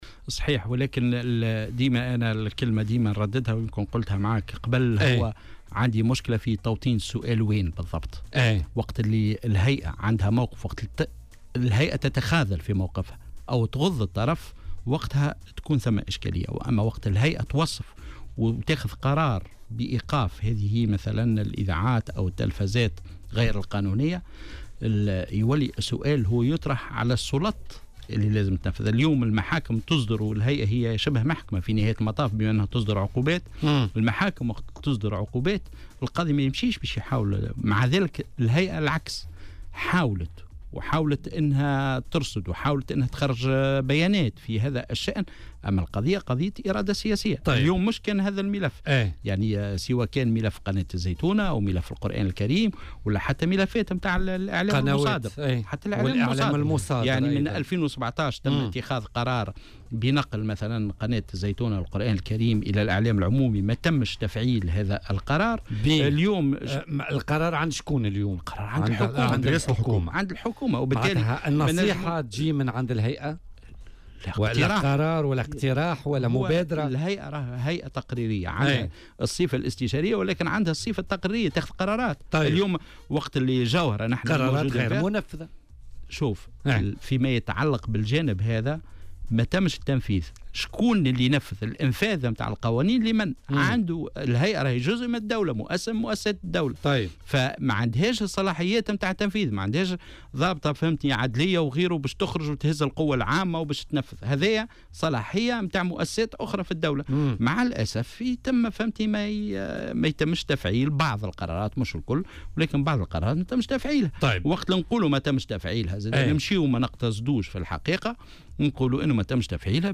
وأضاف السنوسي، ضيف برنامج "بوليتيكا" اليوم الثلاثاء أن القضية قضية إرادة سياسية، مشيرا إلى أنه لم يتم تطبيق بعض قرارات الهيئة على غرار ملف قناة الزيتونة والذي تم اتخاذه منذ سنة 2017 بخصوص نقلها إلى الإعلام العمومي.